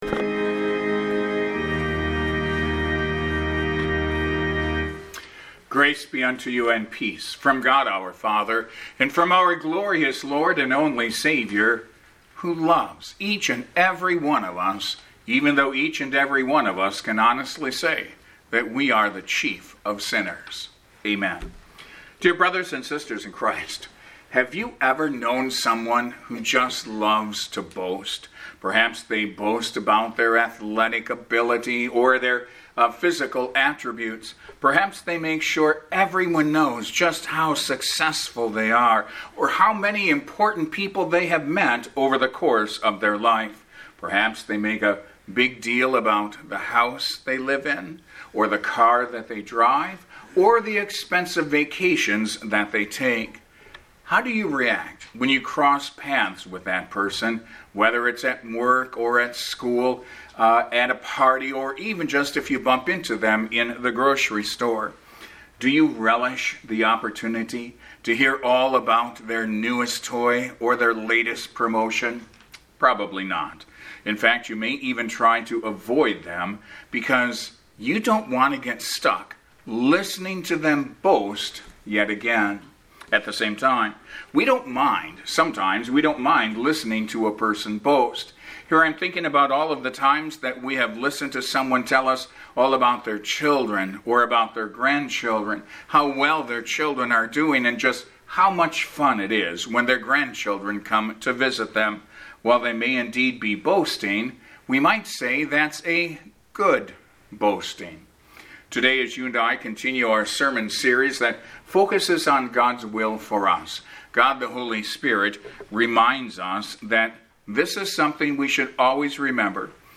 As you and I continue our sermon series on the subject of God’s will, this morning the Holy Spirit focuses our attention on the topic of boasting.